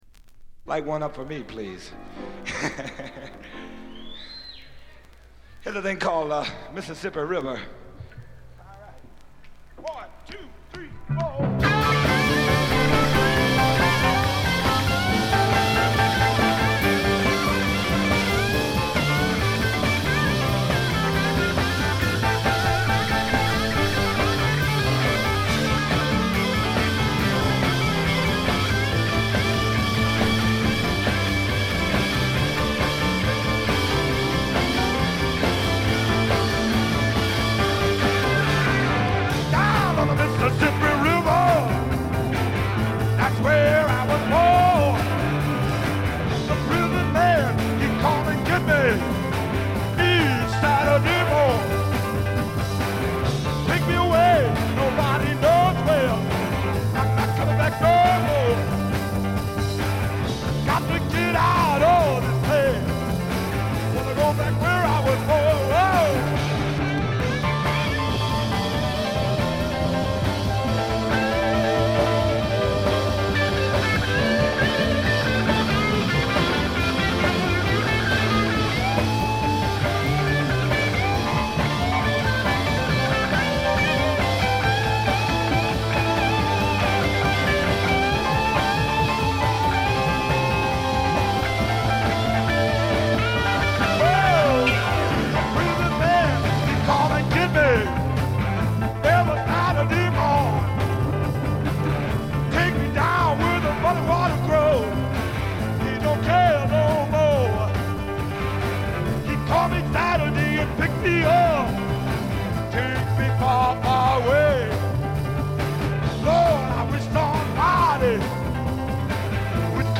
静音部（ほとんどないけど）で軽いチリプチ。
本作は地元デトロイトでのライヴ盤で臨場感にあふれる素晴らしい演奏が聴けますよ。
ヴォーカルもギターも最高です。
試聴曲は現品からの取り込み音源です。
Recorded live at the Eastown Theatre, Detroit, Michigan.